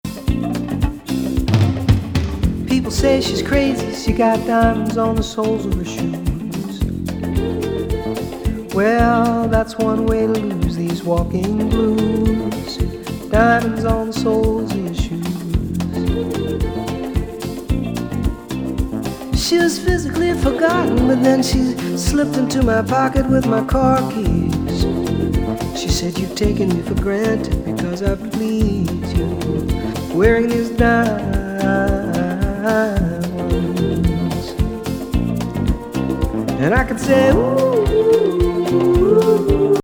南アフリカでの録音作。
ムチャンコ澄み切った気持ちのよい音質とソフィスティケイトされたハイライフとポップスの良いとこ取り。